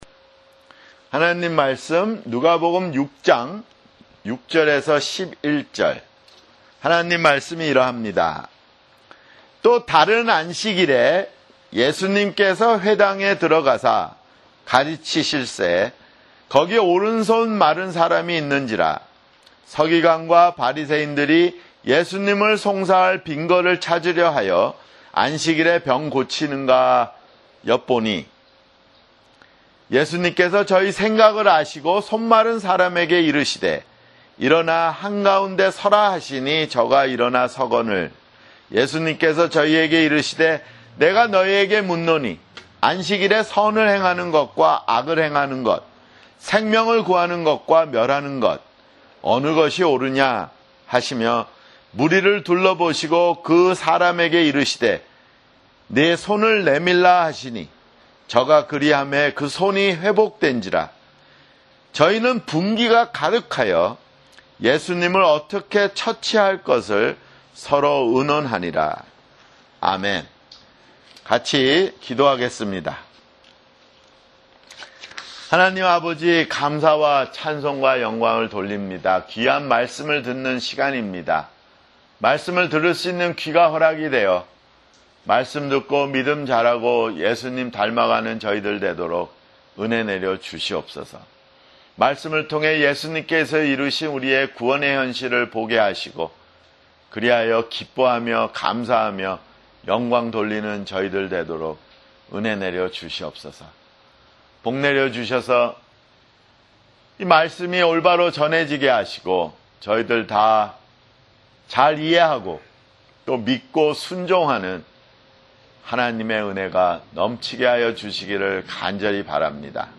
[주일설교] 누가복음 (38)